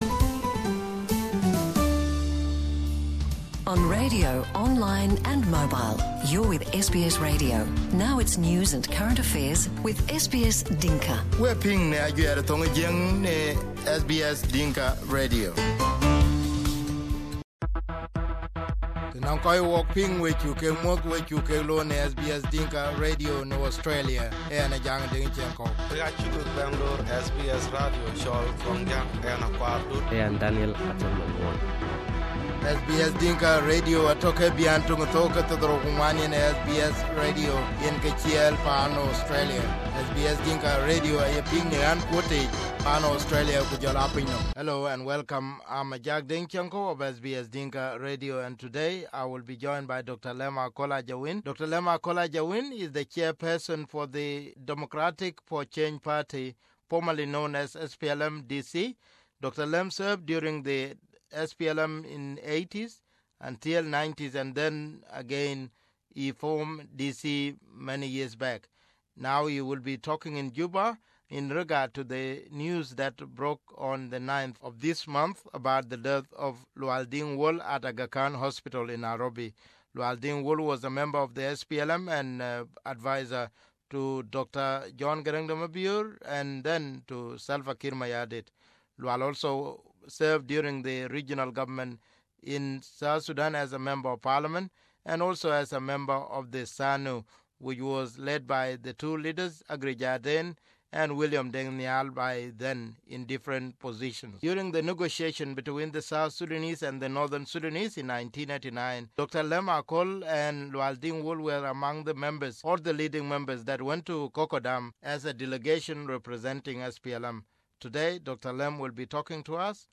We managed to interview Dr Lam Akol of the Democratic Change Party in Juba about Lual's life and politics.